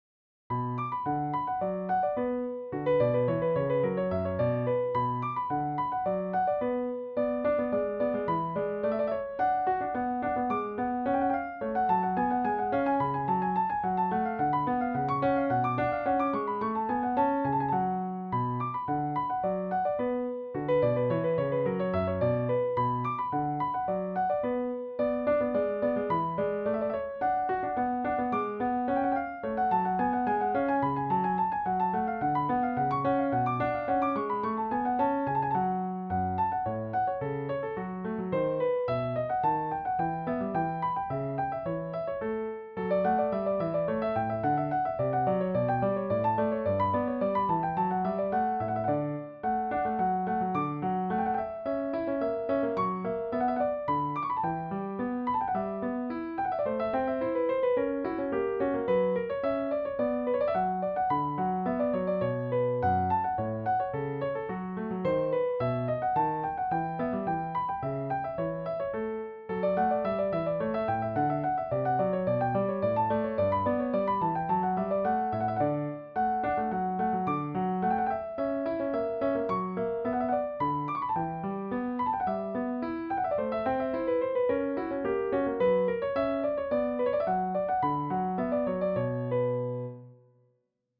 arranged for Piano Solo